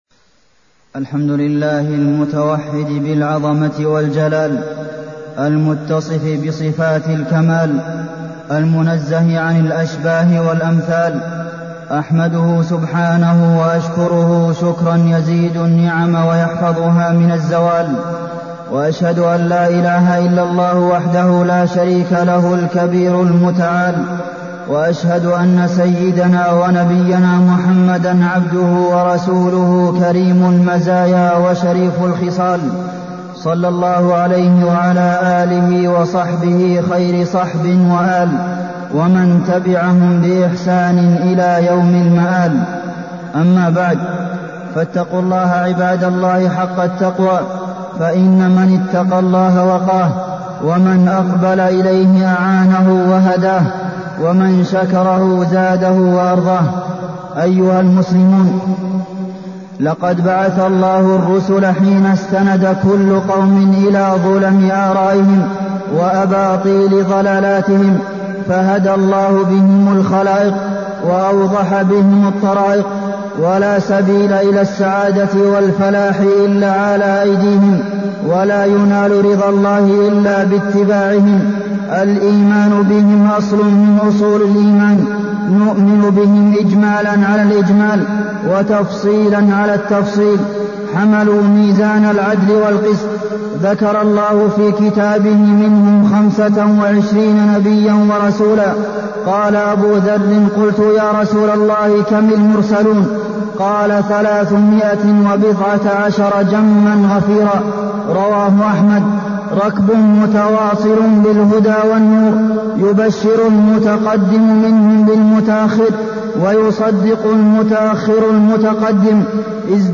تاريخ النشر ١٧ ربيع الثاني ١٤٢٠ هـ المكان: المسجد النبوي الشيخ: فضيلة الشيخ د. عبدالمحسن بن محمد القاسم فضيلة الشيخ د. عبدالمحسن بن محمد القاسم صفات الأنبياء والرسل